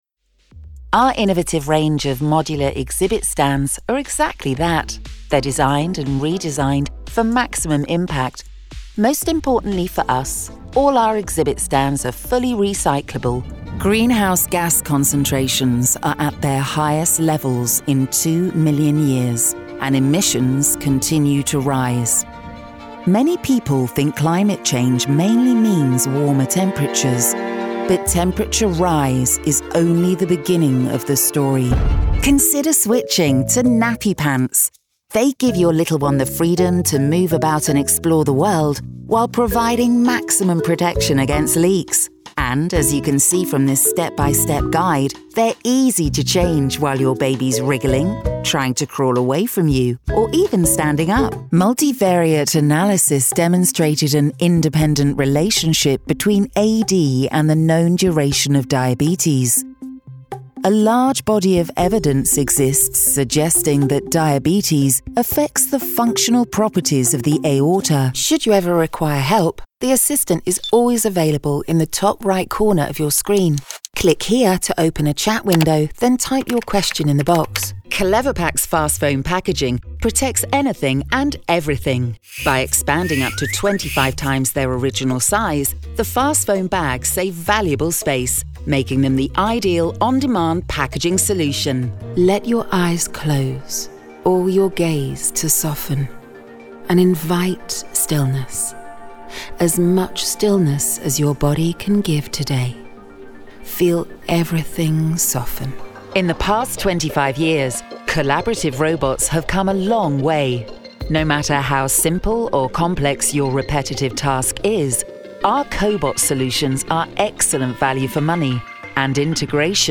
Multi-Nominated Professional British Voiceover. Clear, Contemporary, Confident.
Corporate Reel
Natural RP accent, can also voice Neutral/International and character.
Broadcast-ready home studio working with a Neumann TLM 103 mic.